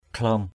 /kʱlɔ:m/ (k.) vậy = donc, ainsi (partic, explét). habar khlaom hbR _A*> để được như vậy = comment donc! to be so; to be like that.